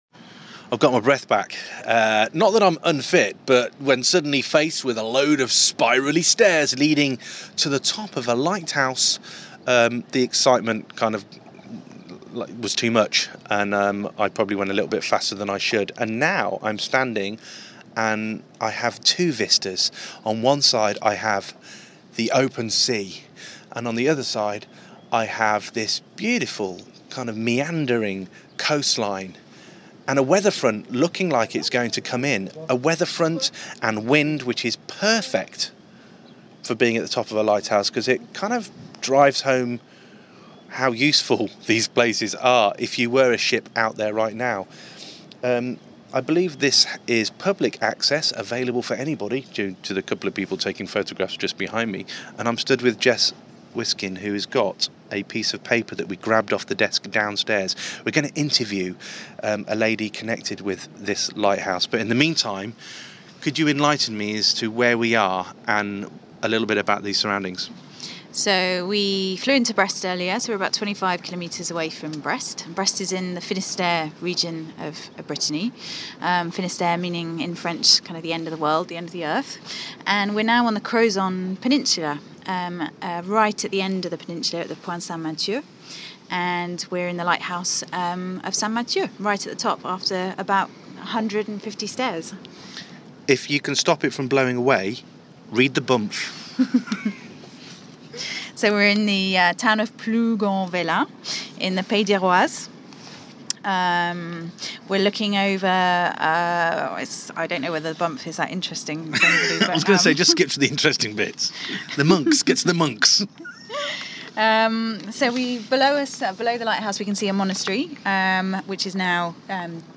Standing on top of a blustery Lighthouse St Mathieu.